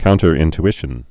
(kountər-ĭnt-ĭshən, -ty-)